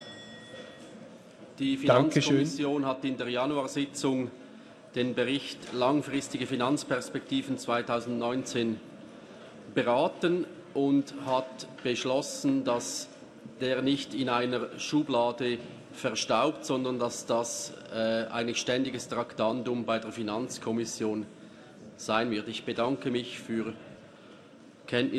Session des Kantonsrates vom 17. bis 19. Februar 2020